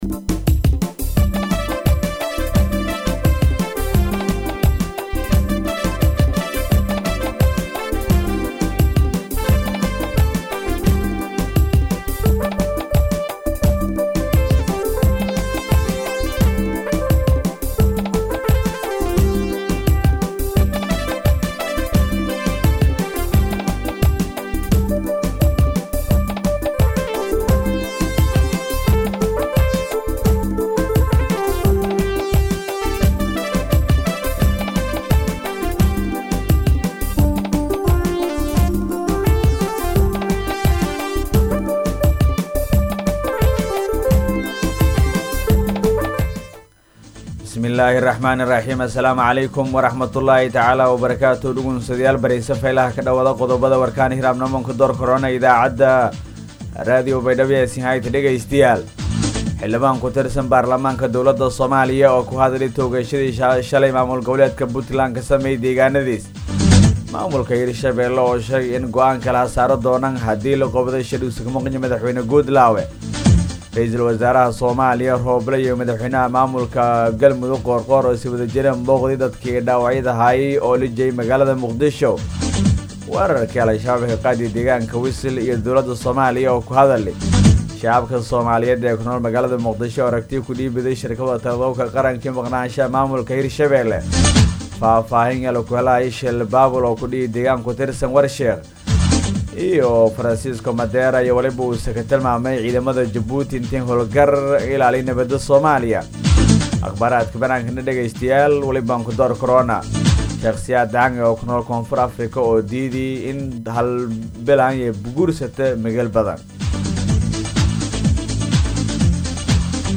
BAYDHABO–BMC:–Dhageystayaasha Radio Baidoa ee ku xiran Website-ka Idaacada Waxaan halkaan ugu soo gudbineynaa Warka maanta ee ka baxay Radio Baidoa.